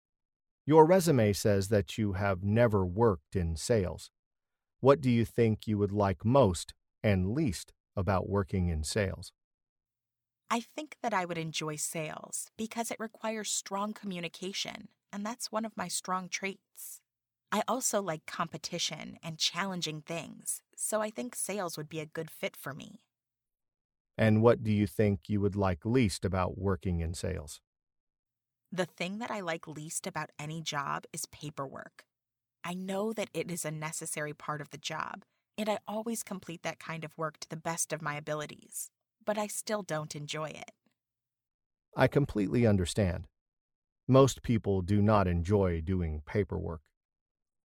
Learn different ways to answer the interview question 'What do you think you would like most and least about working in sales?', listen to an example conversation, and study example sentences.